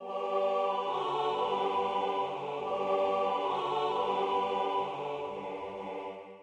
Jauchzet dem Herren, alle Welt (Rejoice in the Lord, all ye lands), SWV 36, is a choral setting of Psalm 100 in German for double choir by Heinrich Schütz.
The text is Psalm 100 in the translation by Martin Luther with an added doxology.[8] The music is set in G major, and begins in a triple metre. The first choir opens with a phrase of two measures, "Jauchzet dem Herren"; it is echoed by the second choir in the second measure, repeated by the first choir in the third measure, and echoed in the fourth.[7]: 1 [6]